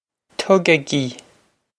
Tugaigí Tug-ah-gee
Pronunciation for how to say
This is an approximate phonetic pronunciation of the phrase.